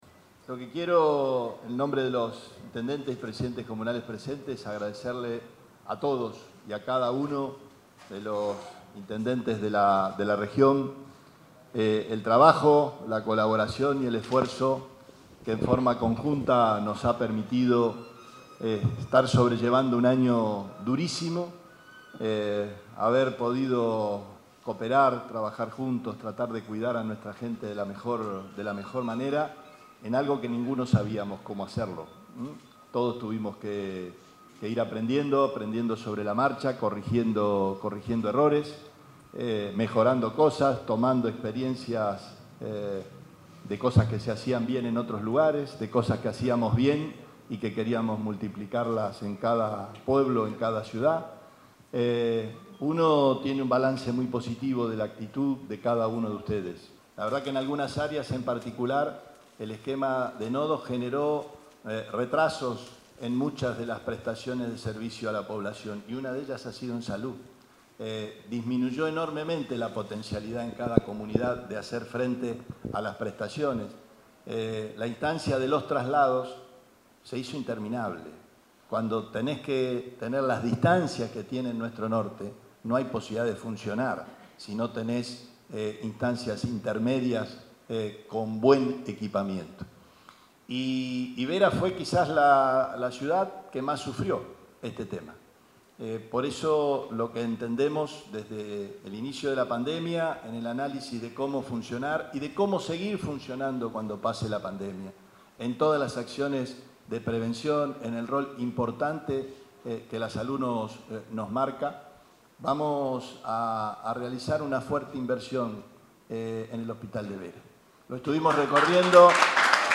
Gobernador Omar Perotti - Vera